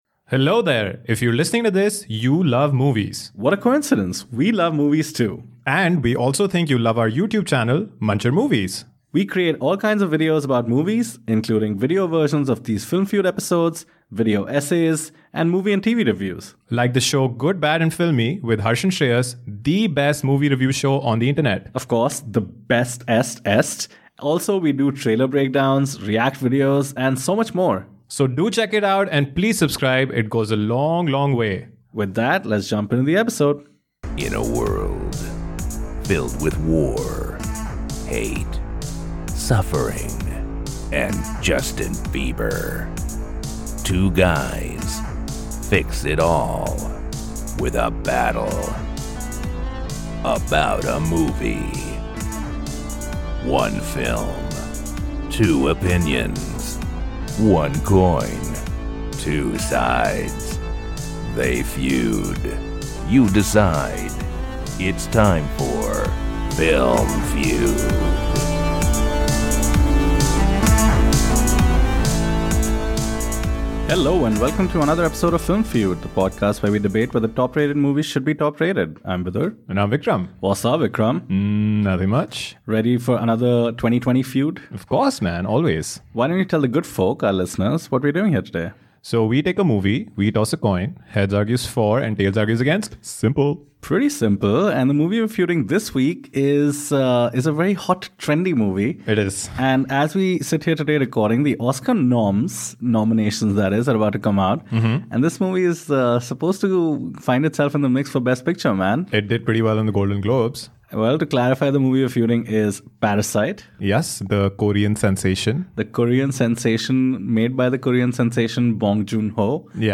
On this week's feud, the boys debate the Academy Award Winner For Best Picture 2020 - Parasite.